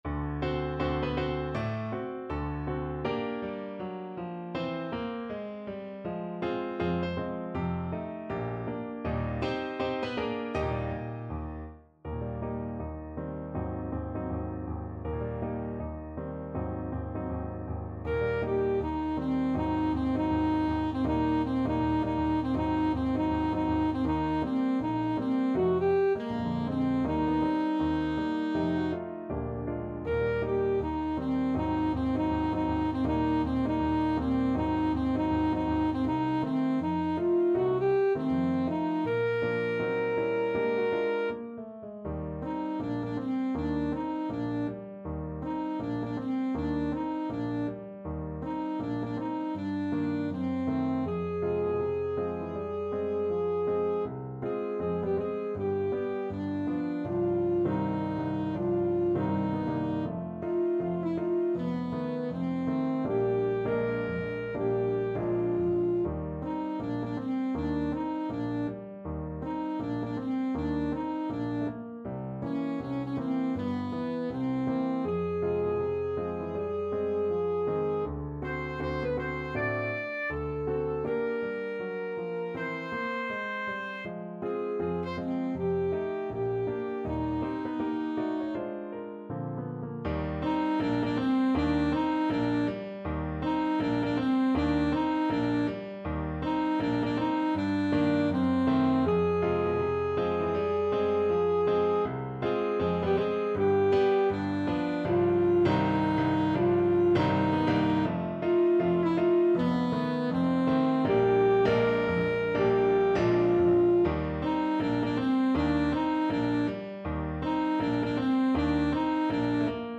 Alto Saxophone
~ = 160 Moderato
Jazz (View more Jazz Saxophone Music)